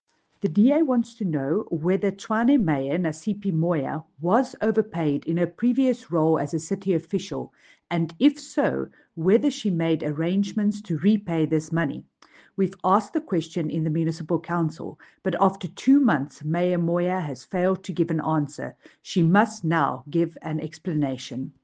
Afrikaans soundbites by Cllr Jacqui Uys